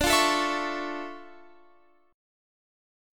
D Minor Major 9th